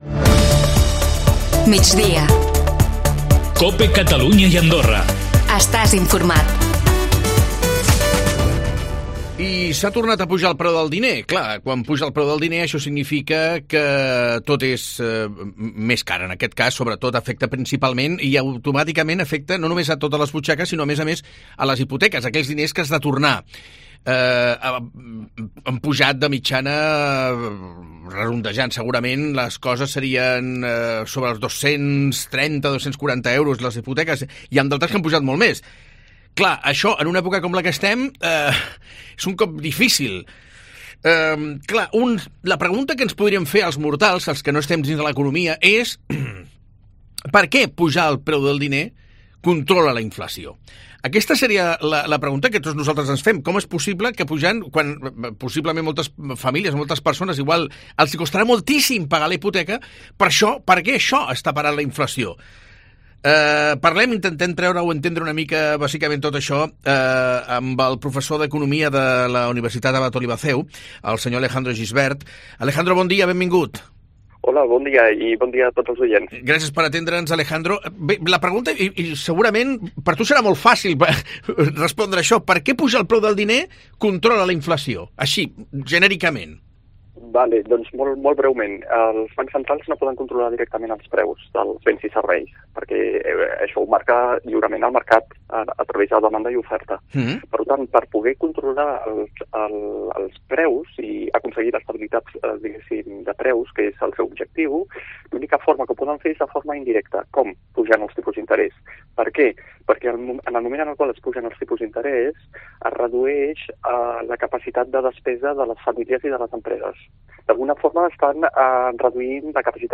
A Migdia a COPE Catalunya hem parlat professor d'economia de la UAO CEU